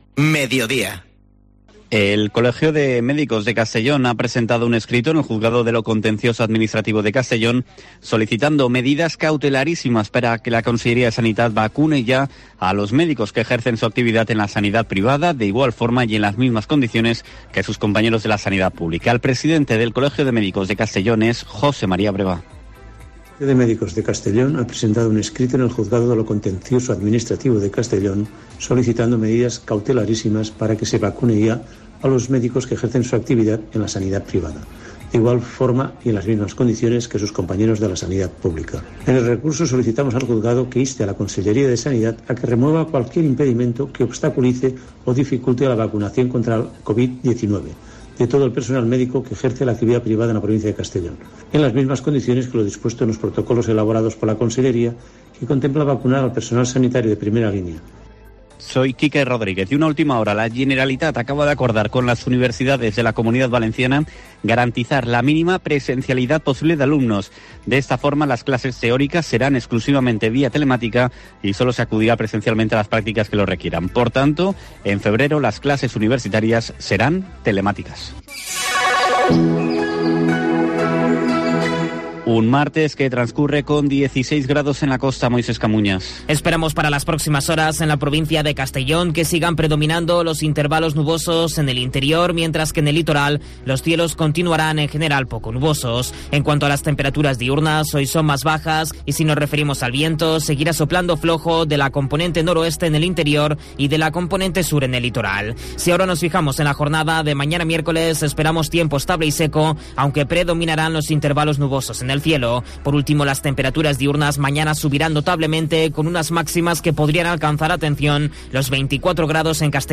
Informativo Mediodía COPE en la provincia de Castellón (26/01/2021)